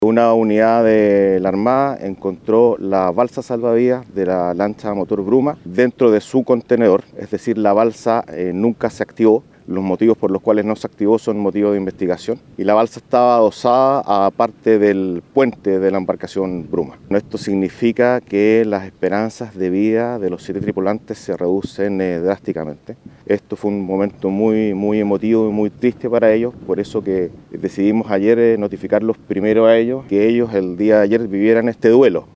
De la balsa de salvamiento correspondiente a la embarcación de los pescadores artesanales, esta ya se encuentra en el continente para ser periciada. Sobre su hallazgo, se refirió el gobernador marítimo de Talcahuano, Sergio Wall.